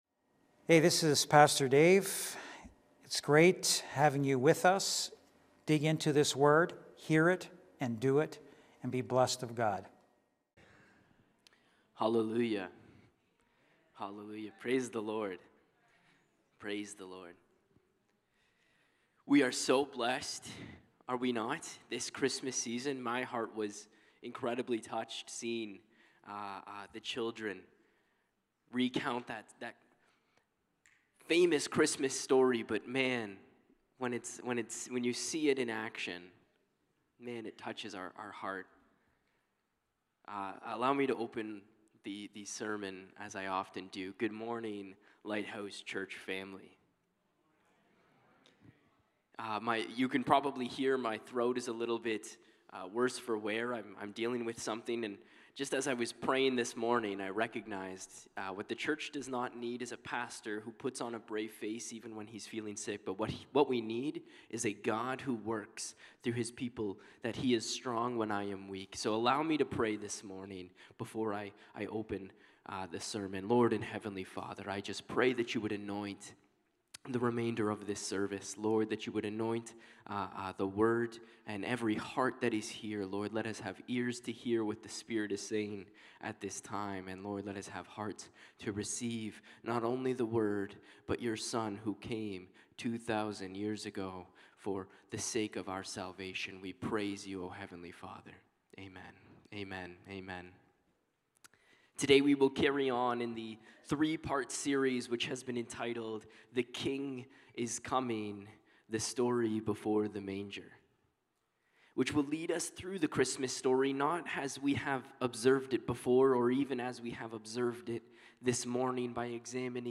Sunday Morning Service
Lighthouse Niagara Sermons